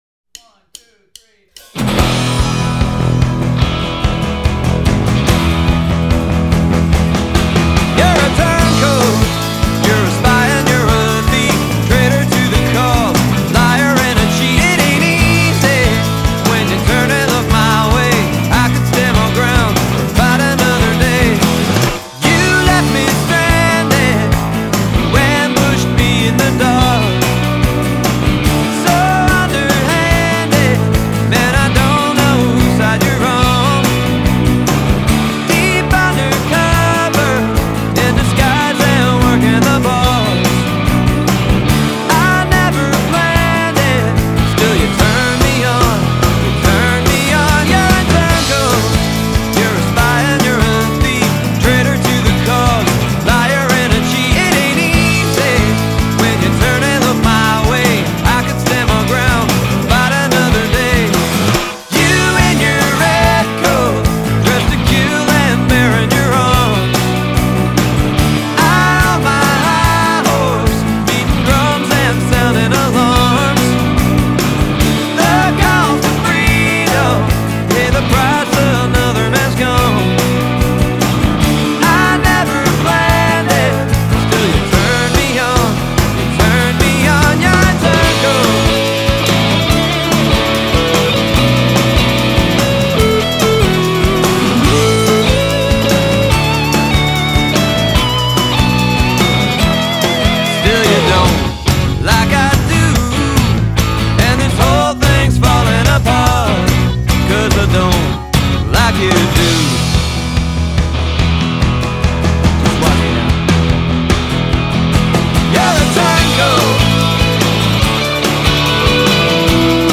The band’s self-titled debut has that 1980s alt western vibe